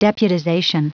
Prononciation du mot deputization en anglais (fichier audio)
Prononciation du mot : deputization